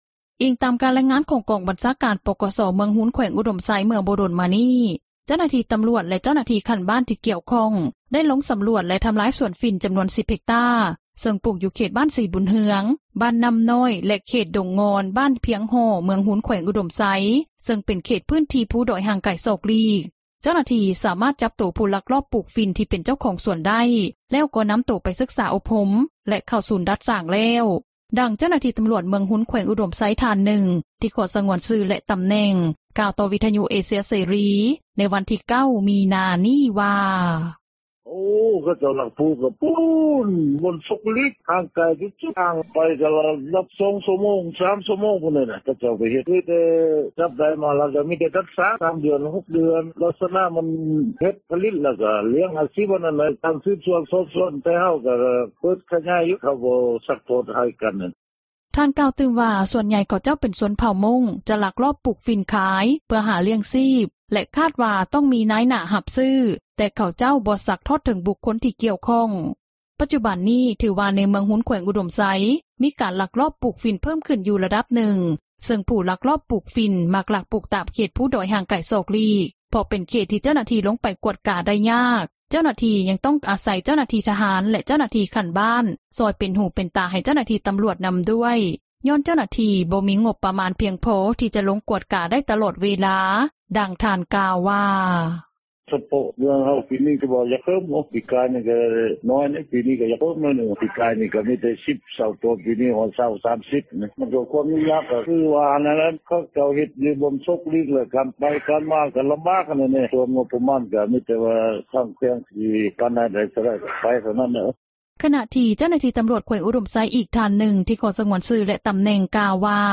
ອີງຕາມການຣາຍງານ ຂອງກອງບັນຊາການ ປກສ ເມືອງຮຸນ ແຂວງອຸດົມໄຊ ເມື່ອບໍ່ດົນມານີ້ ເຈົ້າໜ້າທີ່ຕໍາຣວດ ແລະເຈົ້າໜ້າທີ່ຂັ້ນບ້ານທີ່ກ່ຽວຂ້ອງ ໄດ້ລົງສໍາຣວດ ແລະທໍາລາຍສວນຝິ່ນ ຈໍານວນ 10 ເຮັກຕ້າຣ໌ ຊຶ່ງປູກຢູ່ເຂດບ້ານສີບຸນເຮືອງ, ບ້ານນໍ້ານ້ອຍ ແລະເຂດດົງງອນ ບ້ານພຽງຫໍ້ ເມືອງຮຸນ ແຂວງອຸດົມໄຊ ຊຶ່ງເປັນເຂດພື້ນທີ່ພູດອຍ ຫ່າງໄກສອກຫຼີກ ເຈົ້າໜ້າທີ່ສາມາດຈັບຜູ້ລັກລອບປູກຝິ່ນ ທີ່ເປັນເຈົ້າຂອງສວນຝິ່ນໄດ້ ແລ້ວກໍນໍາໂຕໄປສຶກສາອົບຮົມ ແລະເຂົ້າສູນດັດສ້າງແລ້ວ, ດັ່ງເຈົ້າໜ້າທີ່ຕໍາຣວດ ເມືອງຮຸນແຂວງອຸດົມໄຊ ທ່ານນຶ່ງ ທີ່ຂໍສງວນຊື່ແລະຕໍາແໜ່ງ ກ່າວຕໍ່ວິທຍຸເອເຊັຽເສຣີ ໃນວັນທີ່ 9 ມິນາ ນີ້ວ່າ: